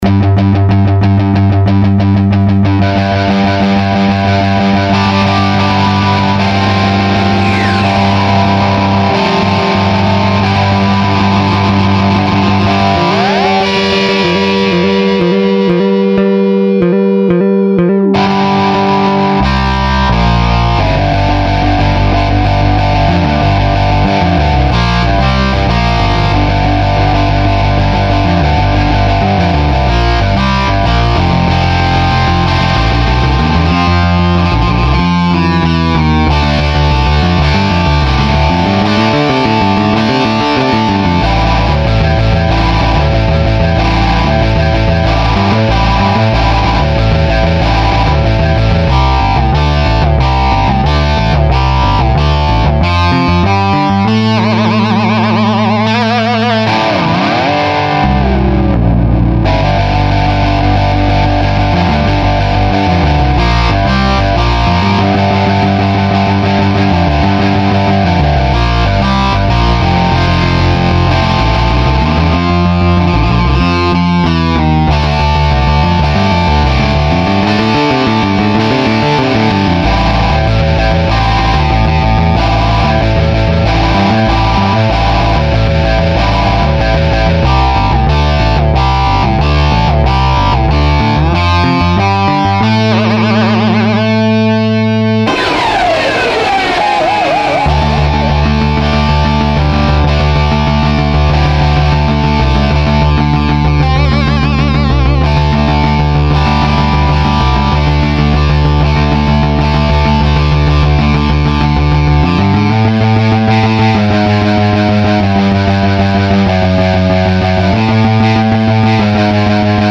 ●LesPaulP90●Telecaster